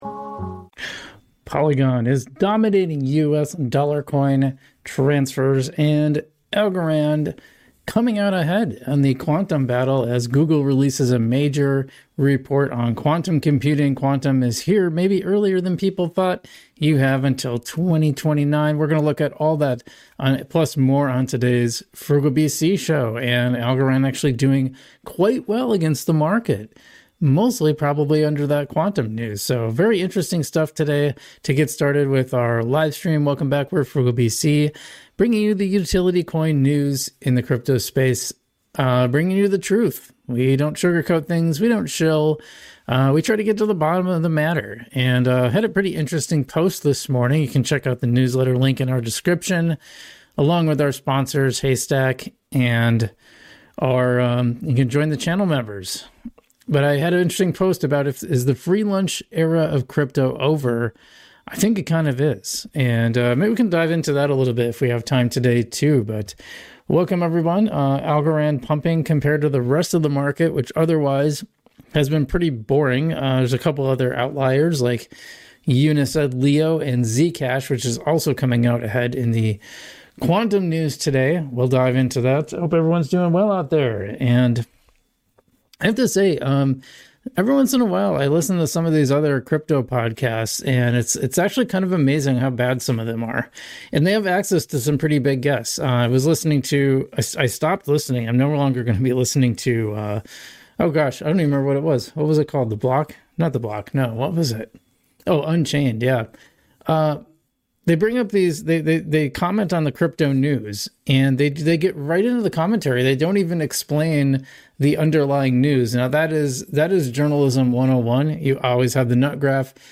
In This Livestream: Polygon’s Monthly ATH: How 3.51 million senders just flipped the script on stablecoin dominance.